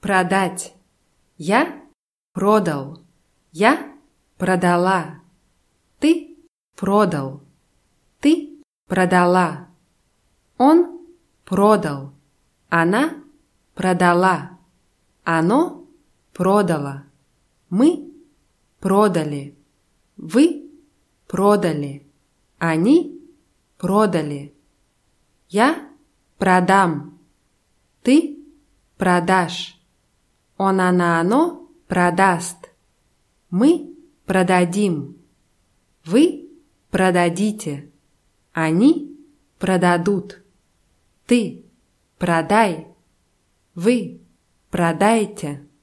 продать [pradátʲ]